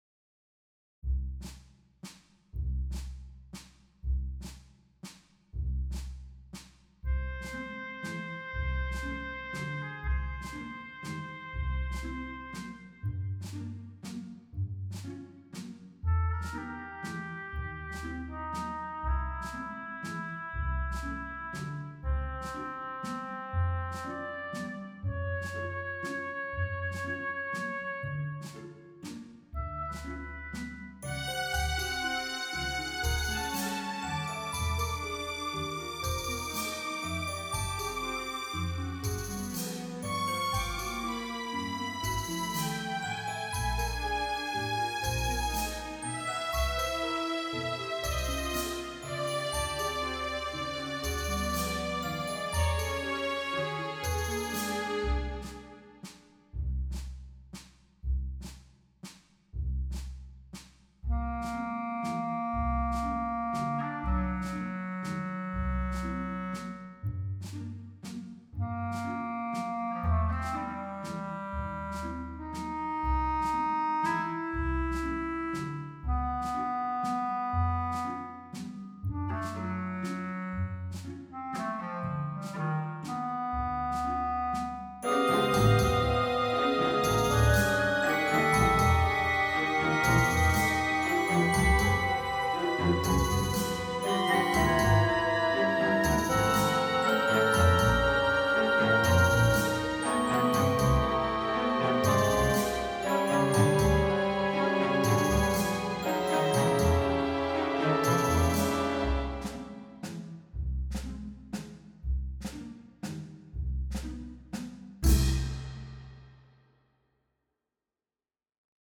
Three Episodes for Chamber Orchestra